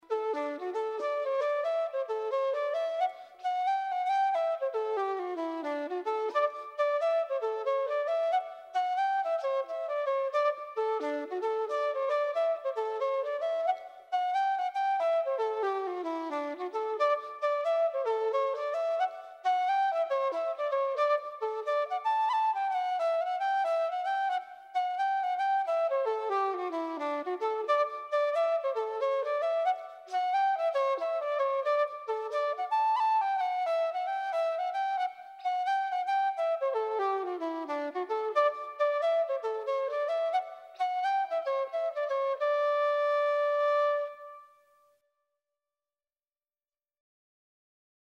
Simple Audio -- Piano